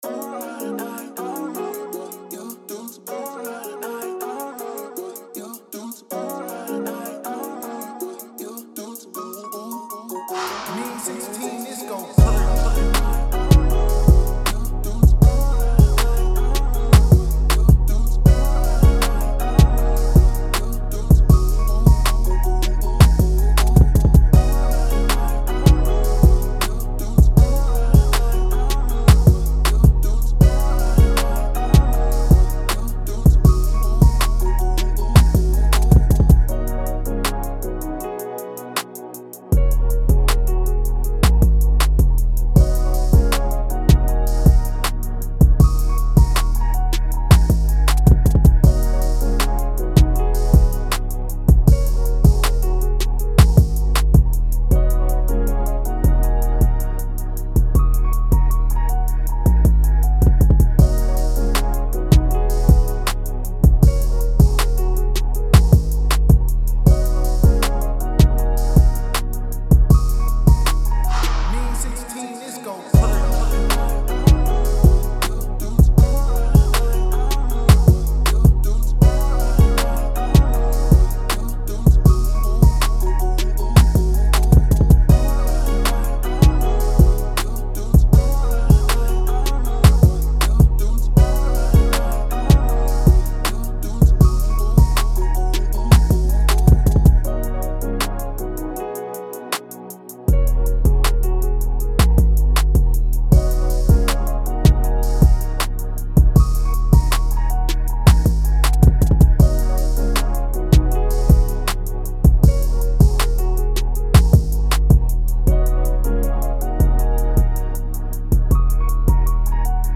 D#-Min 158-BPM